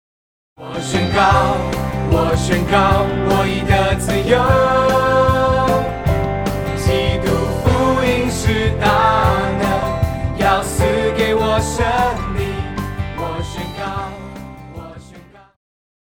套鼓(架子鼓)
乐团
教会音乐
演奏曲
独奏与伴奏
有主奏
有节拍器